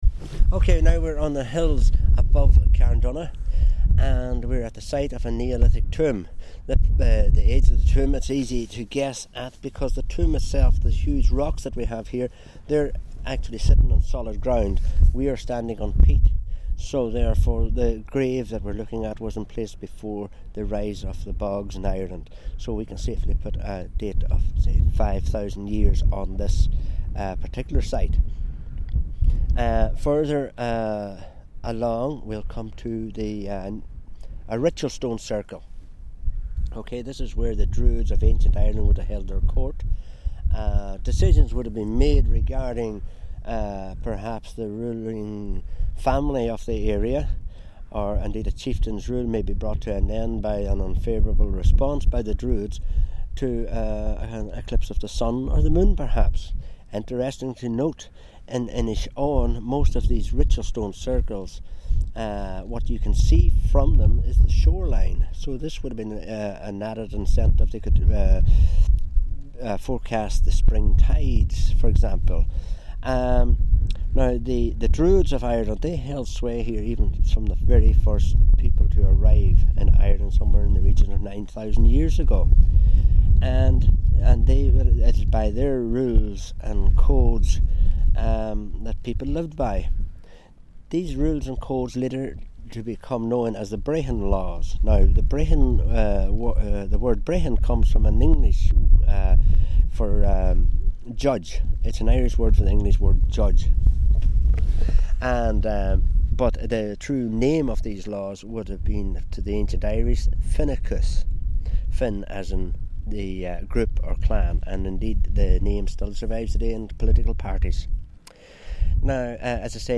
out and about in Inishowen